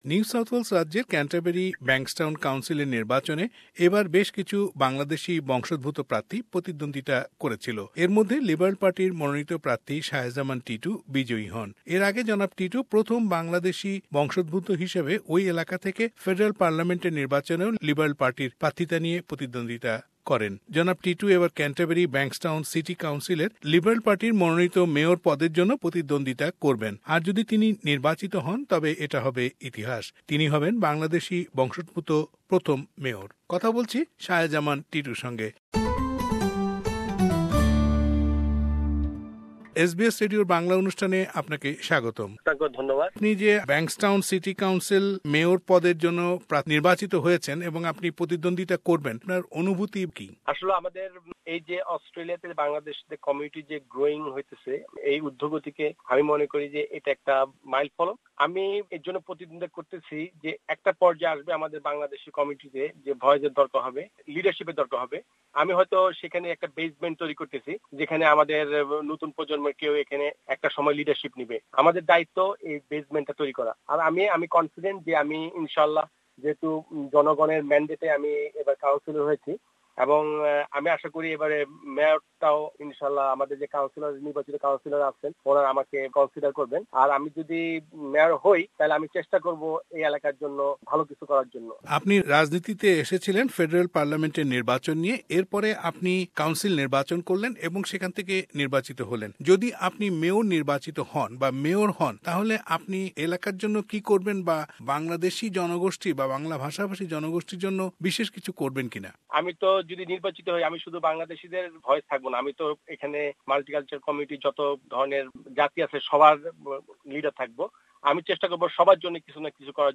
Interview with Shahe Zaman Titu Liberal Party councillor of Canterbury-Bankstown council